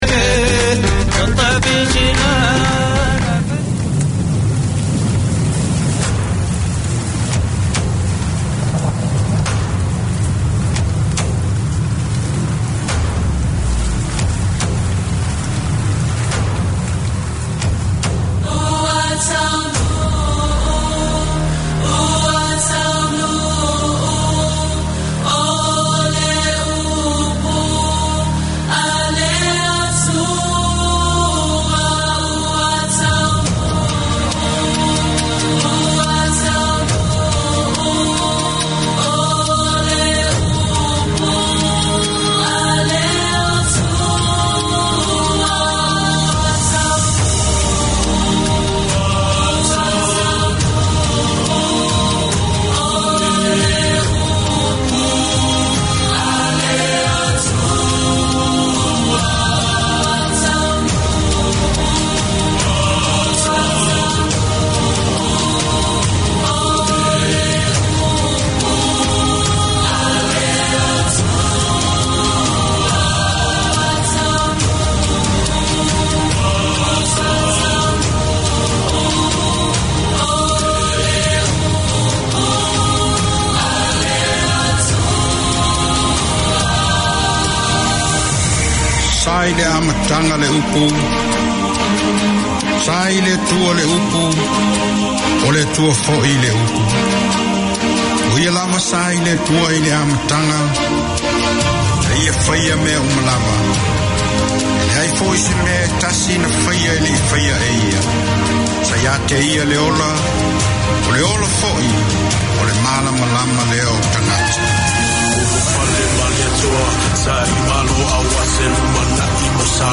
Te Ama Pasefika Health is promoting the well-being of Pacific people. Each week you’ll hear interviews with studio guests giving advice on health, education, employment and other support services that encourage wellness and foster healthy, happy lives for Pasefika people in New Zealand.